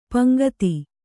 ♪ paŋgati